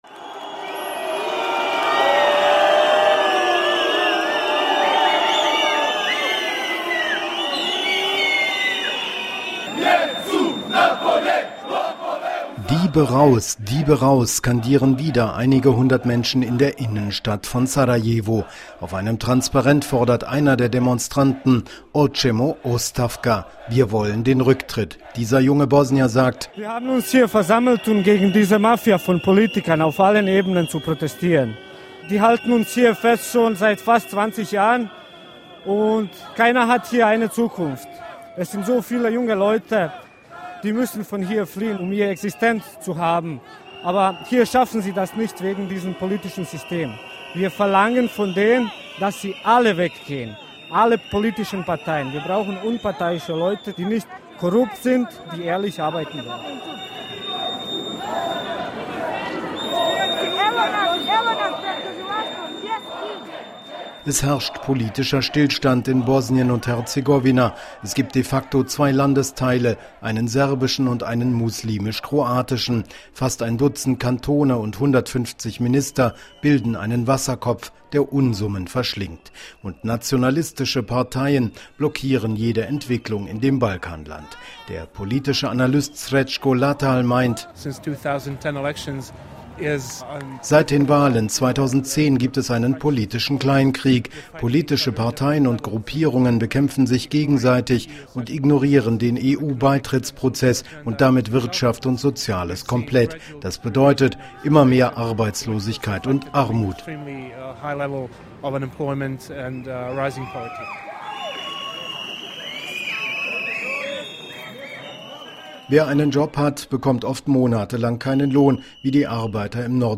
„Diebe raus!“ rufen Demonstranten auch heute in Sarajevo und anderen Städten Bosnien-Herzegowinas.